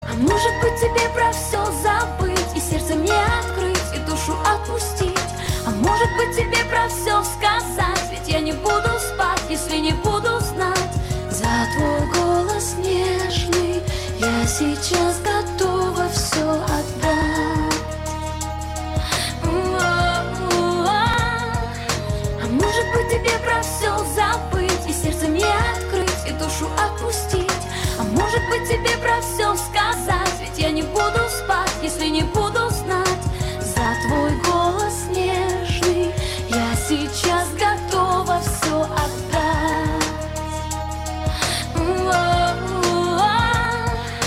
поп
красивые
женский вокал
грустные
романтичные